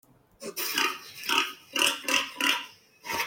Oink oink
Cerdo (audio/mpeg)